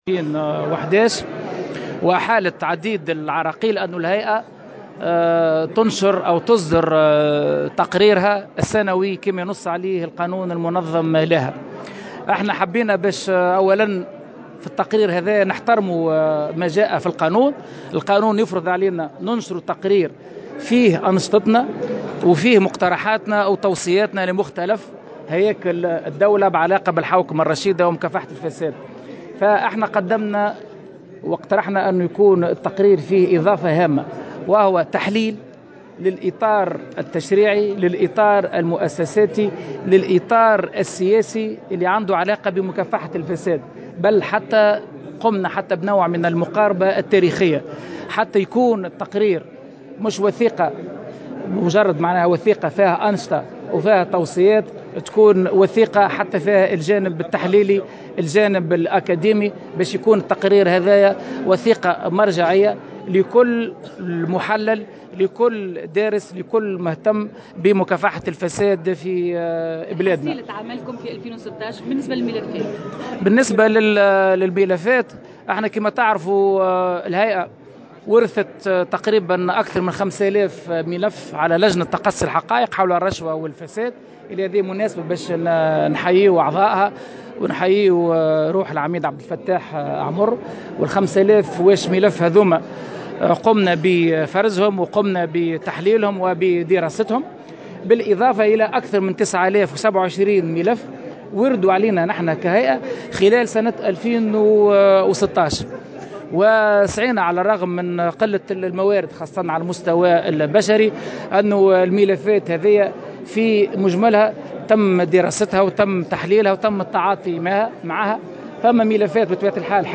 كشف رئيس الهيئة الوطنية لمكافحة الفساد الطبيب في تصريح لمراسلة الجوهرة "اف ام" على هامش ندوة صحفية عقدتها الهيئة اليوم الخميس حول تقريريها السنوي أن الهيئة تلقت 9027 عريضة 5594 منها وردت على الهيئة على الرقم الأخضر، و275 عن طريق البريد الالكتروني، فيما تم تسجيل 2200 منها بمكتب الضبط، وإحالة 958 منها من رئاسة الحكومة.